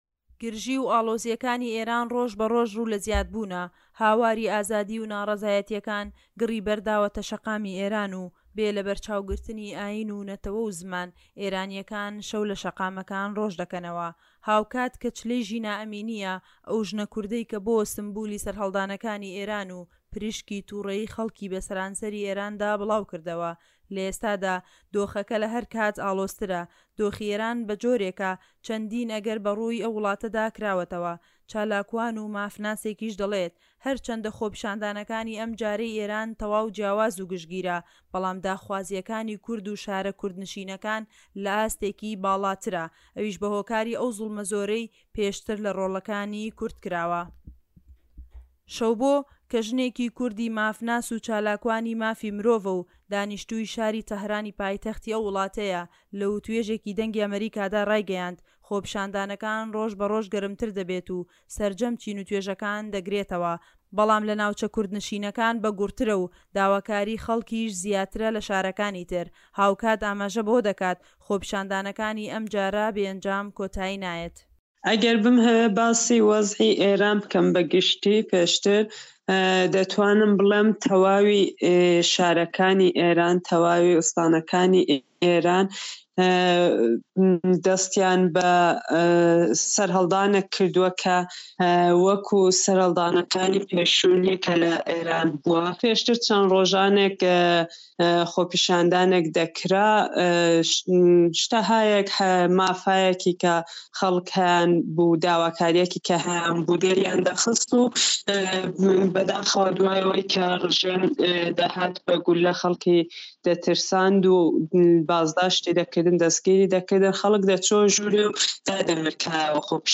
دەقی ڕاپۆرتی